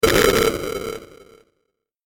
دانلود آهنگ بمب 14 از افکت صوتی اشیاء
جلوه های صوتی
دانلود صدای بمب 14 از ساعد نیوز با لینک مستقیم و کیفیت بالا